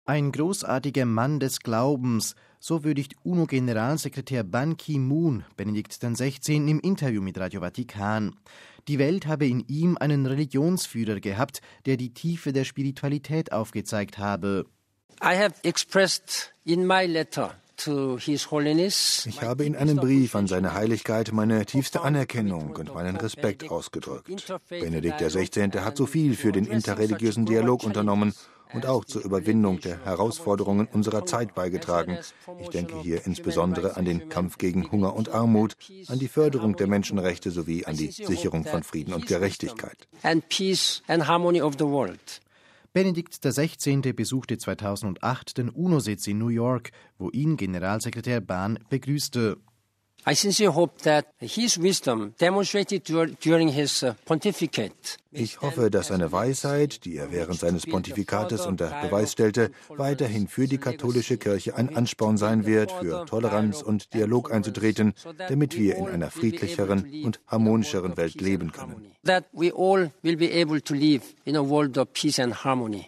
MP3 Ein großartiger „Mann des Glaubens“: So würdigt UNO-Generalsekretär Ban Ki-moon Benedikt XVI. im Interview mit Radio Vatikan. Die Welt habe in ihm einen Religionsführer gehabt, der die Tiefe der Spiritualität aufgezeigt habe.